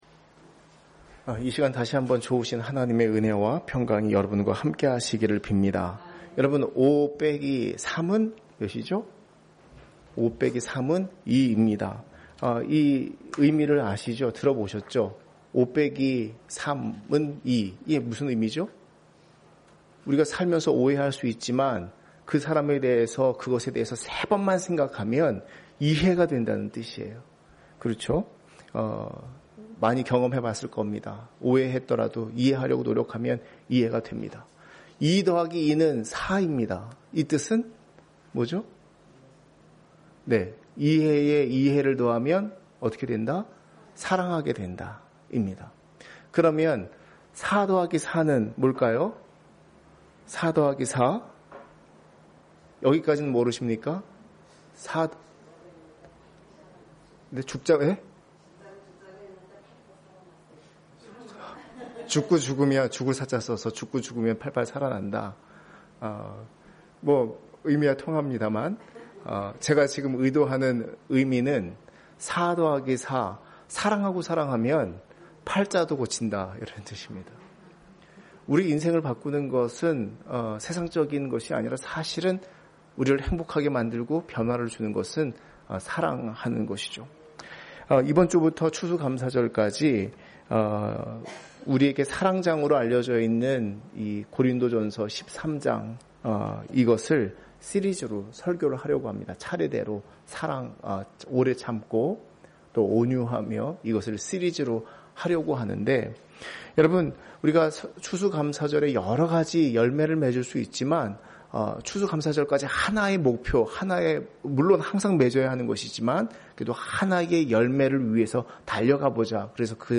이사야54:4 사랑을 위한 시리즈 설교 5-3=2, 오해하고 있을 때, 세 번만 참고 생각하면 이해가 된다는 뜻. 2+2=4, 이해하고 이해하면 사랑하게 된다는 뜻, 4+4=8, 이 뜻은?